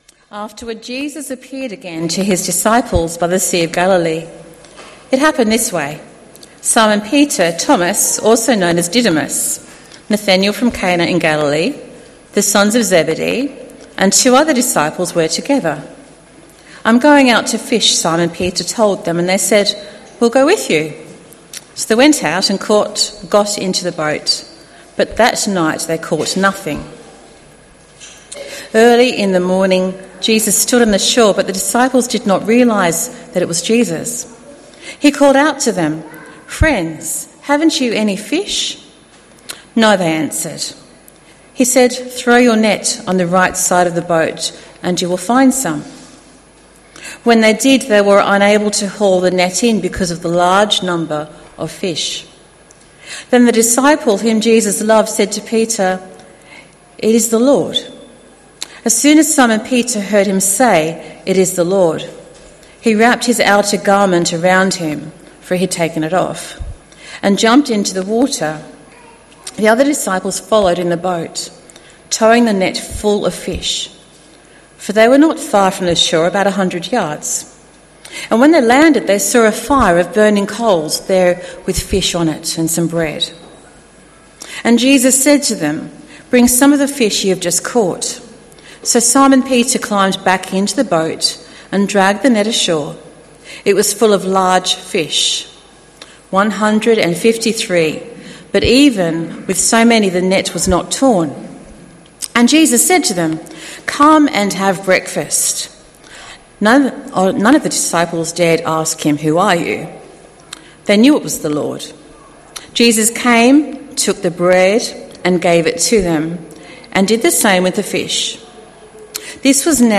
Bible readings and sermon from the 10AM meeting at Newcastle Worship & Community Centre of The Salvation Army. The Bible readings were taken from Luke 21:1-25.